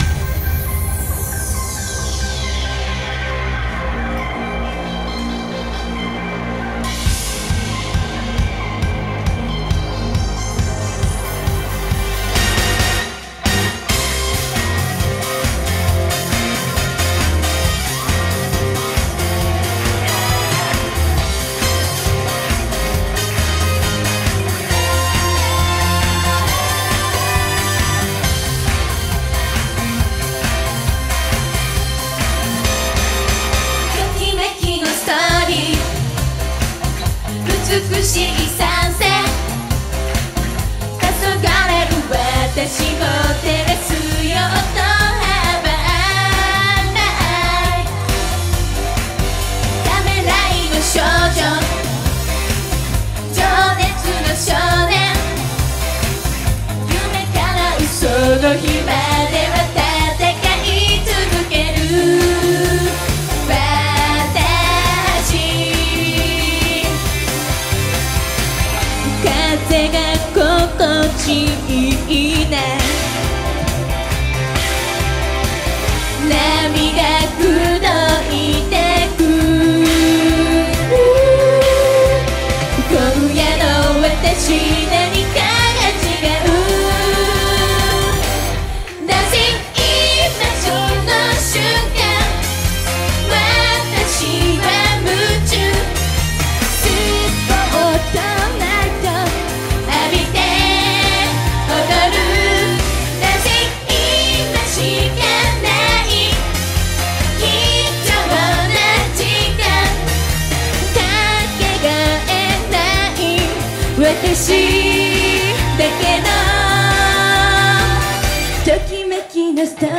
Taken from the live performance.